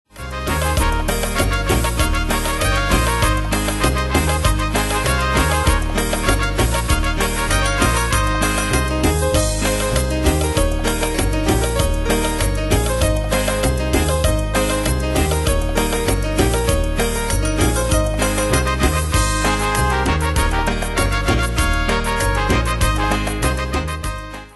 Style: Latino Ane/Year: 1996 Tempo: 98 Durée/Time: 3.09
Danse/Dance: Cumbia Cat Id.
Pro Backing Tracks